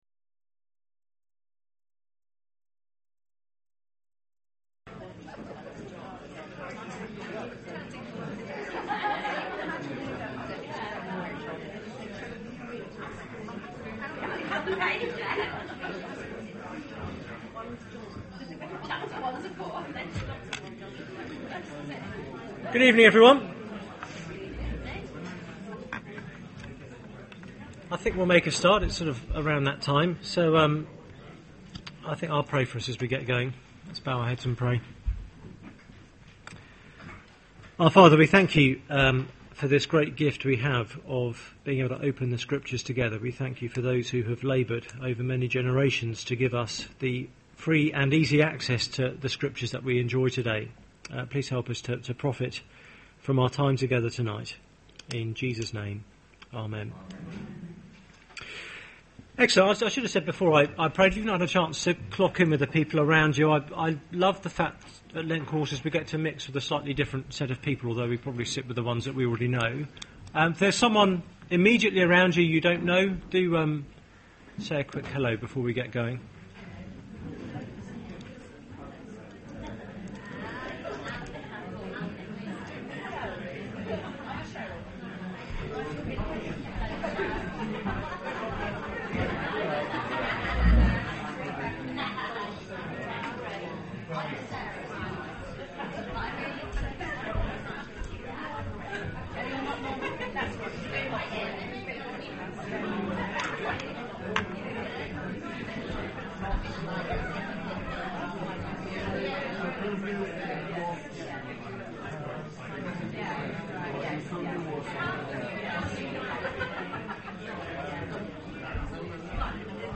Media for Seminar on Tue 29th Mar 2011 20:00 Speaker